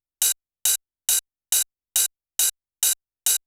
OP HH     -R.wav